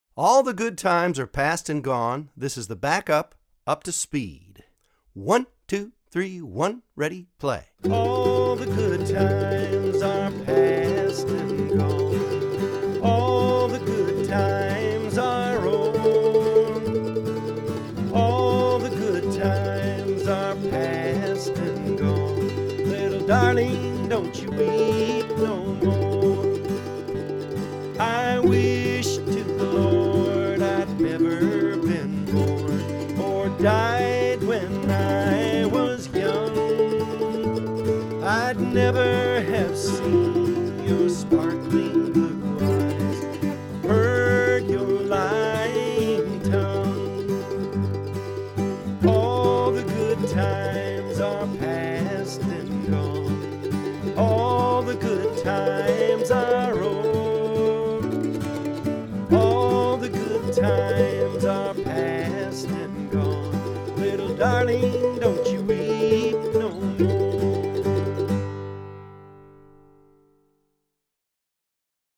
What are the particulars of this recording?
Online Audio (both slow and regular speed)